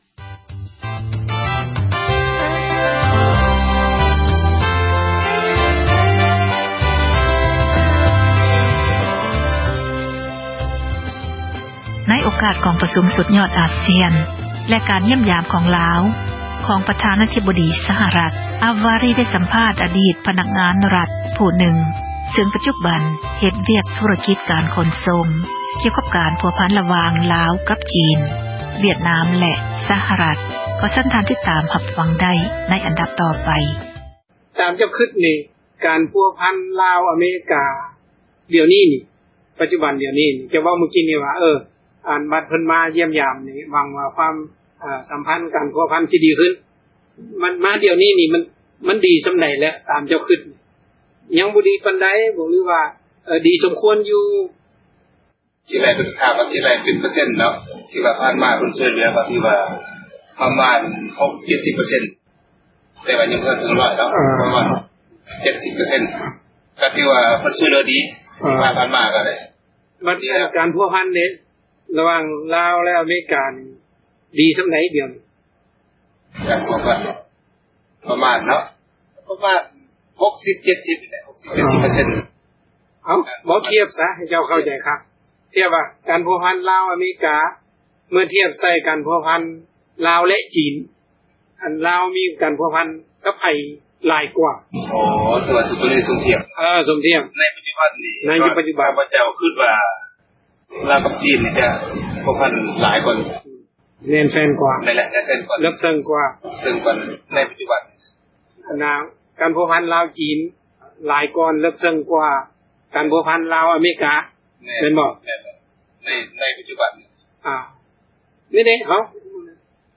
ສໍາພາດອະດີດພະນັກງານ ຣັຖ